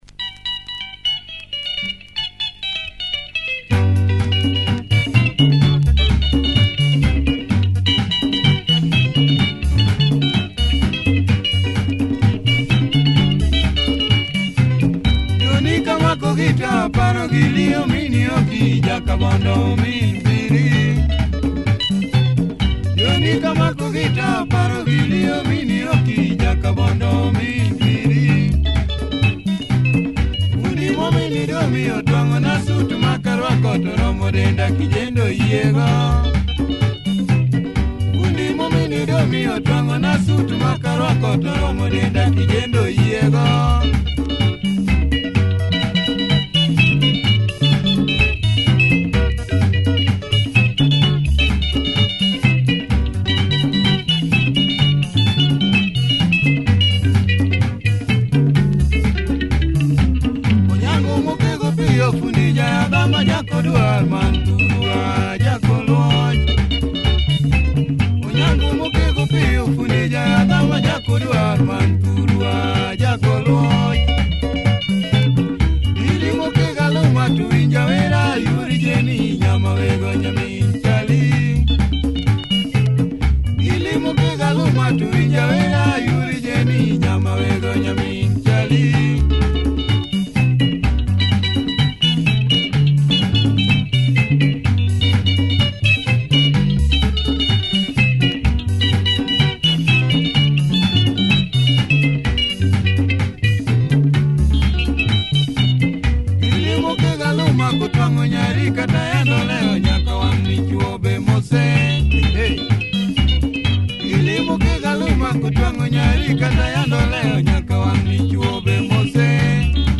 Nice early luo benga, check audio of both sides. https